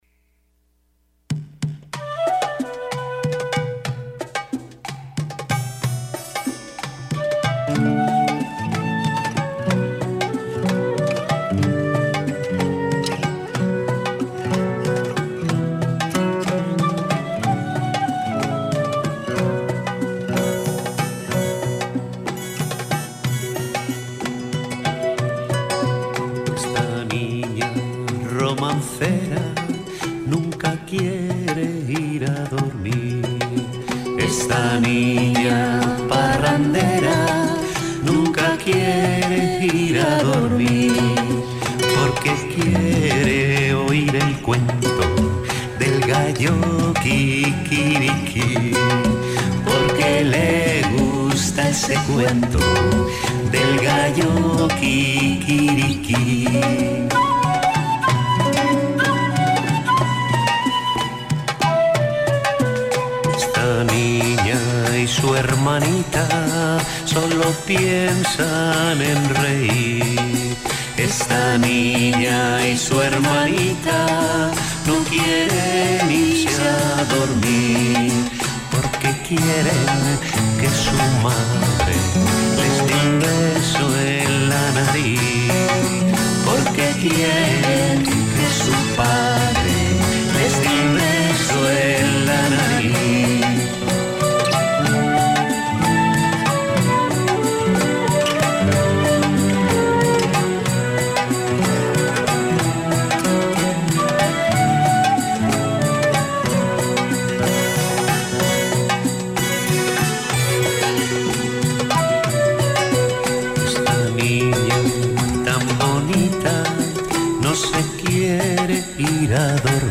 En lo que deciden escuchen musiquita y muchas, muchas cartas.